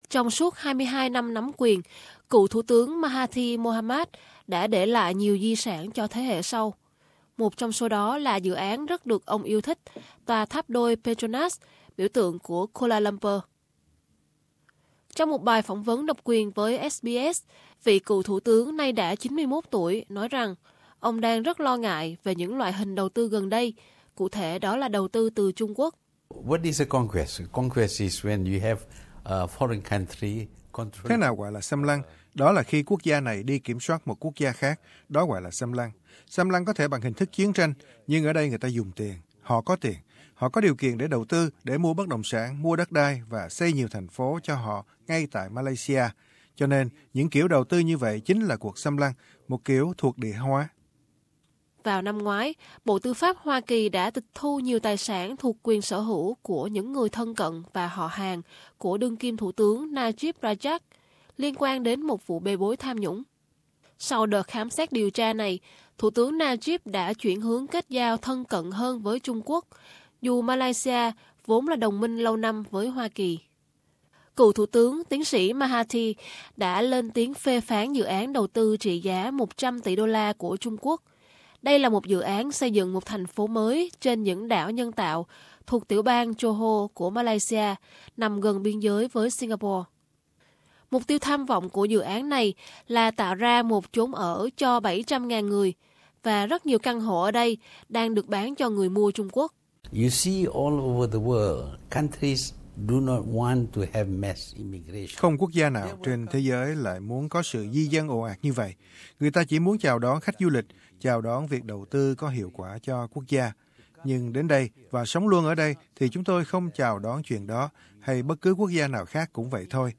Trong một cuộc phỏng vấn độc quyền với SBS, cựu thủ tướng Malaysia, Mahathir Mohamad cho biết sẽ ra tranh cử vào cuối năm nay. Ông cũng chỉ trích đương kim thủ tướng Najib Razak đã để Trung Quốc đầu tư ồ ạt, gây quan ngại về một loại hình thuộc địa hóa.